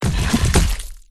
Death4.wav